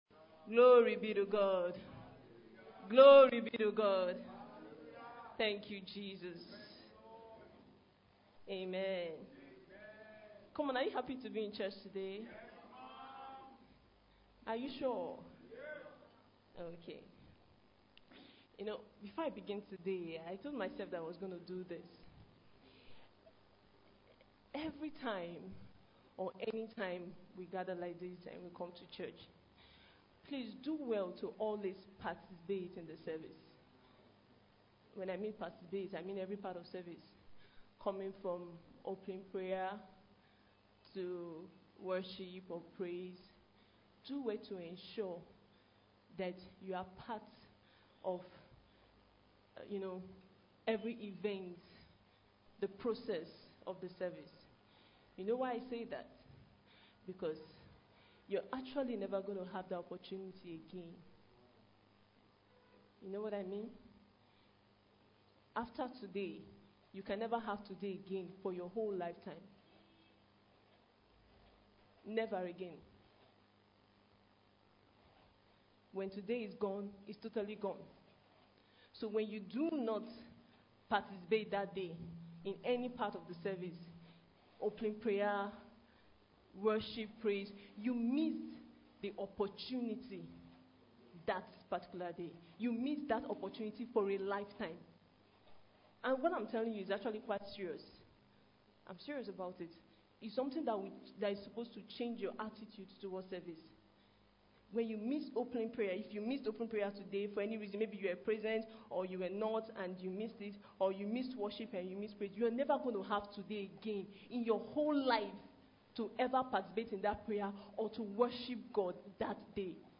Click the link below to listen to this powerful teaching that will challenge and empower you to take responsibility for your life and destiny.